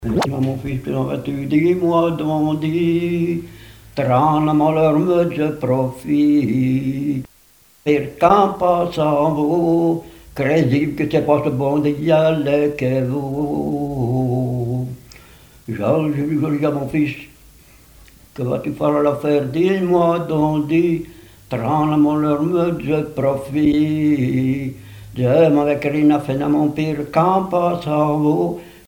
chansons et témoignages parlés
Pièce musicale inédite